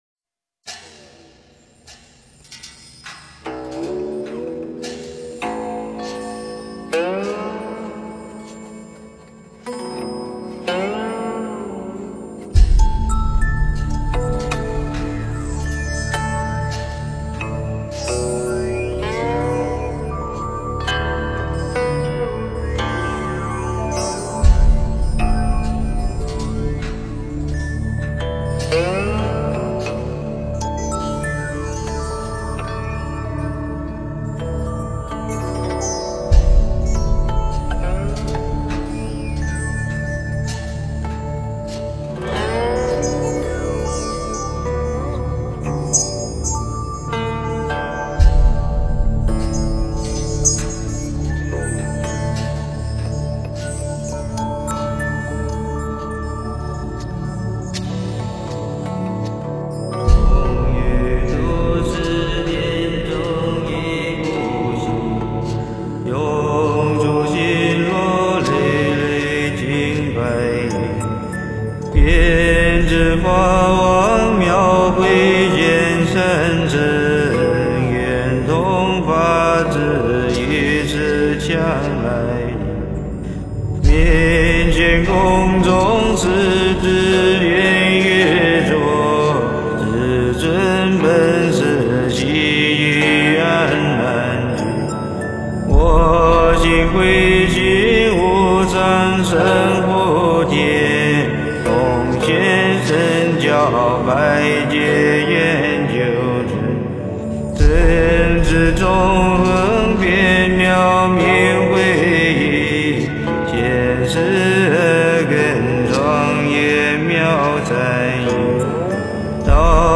宗喀巴上师赞 - 诵经 - 云佛论坛
佛音 诵经 佛教音乐 返回列表 上一篇： 药师佛心咒 下一篇： Aad Guray Nameh